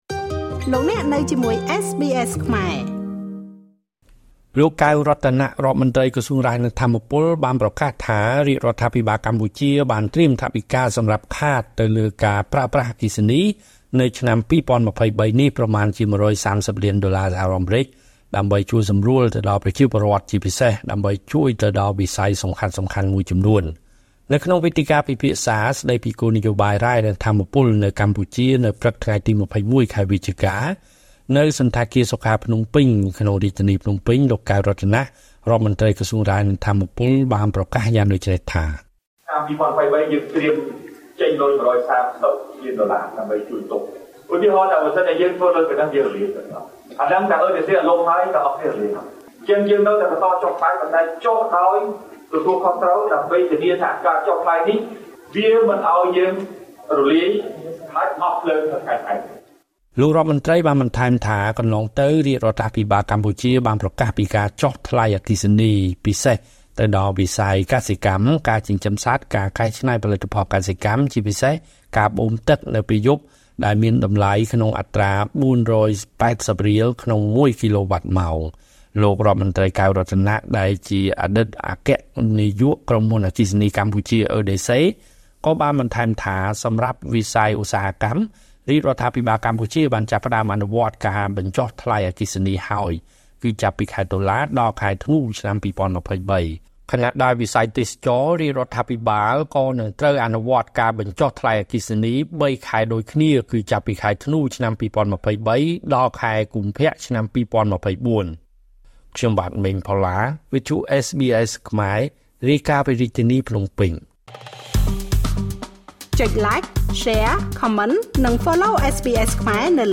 នៅក្នុងវេទិកាពិភាក្សា ស្តីពី គោលនយោបាយរ៉ែ និងថាមពល នៅកម្ពុជា នៅព្រឹកថ្ងៃទី២១ ខែវិច្ឆិកា ឆ្នាំ២០២៣នេះ នៅសណ្ឋាគារ សុខា ភ្នំពេញ លោក កែវ រតនៈ រដ្ឋមន្រ្តីក្រសួងរ៉ែ និងថាមពល បានប្រកាសយ៉ាងដូច្នេះថា៖